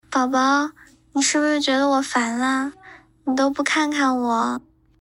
声音克隆效果：
原声效果：